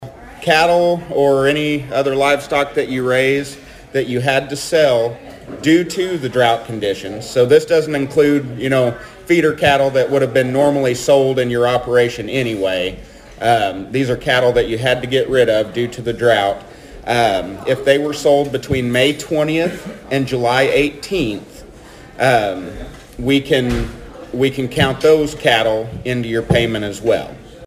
The Thayer and Mammoth Spring Rotary met Wednesday for their weekly meeting.